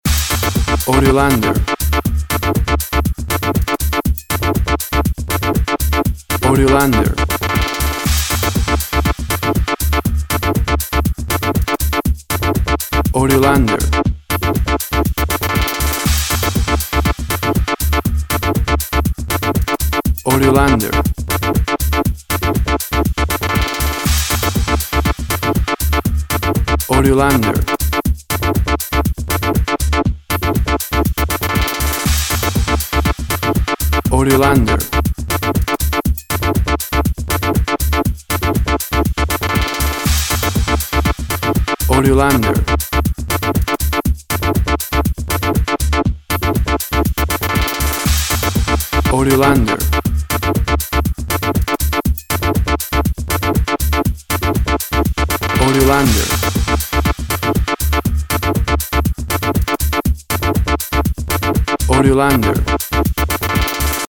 WAV Sample Rate 16-Bit Stereo, 44.1 kHz
Tempo (BPM) 122